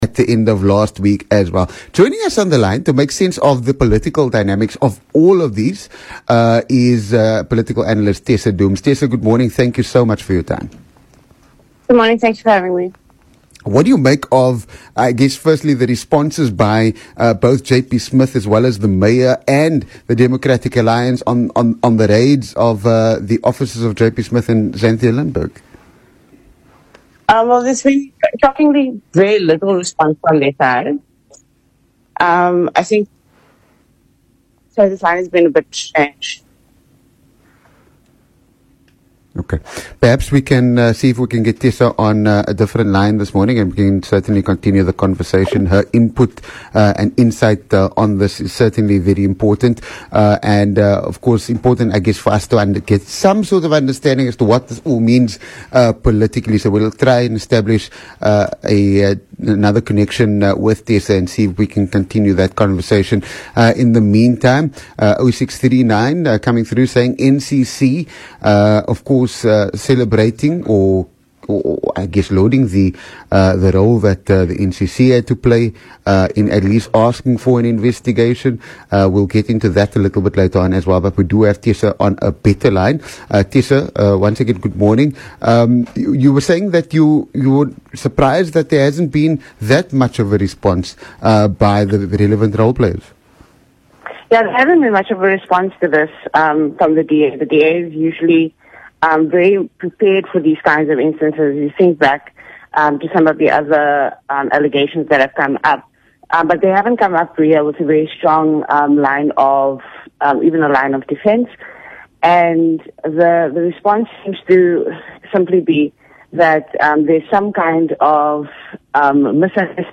Speaking on VOC Breakfast on Monday, political analyst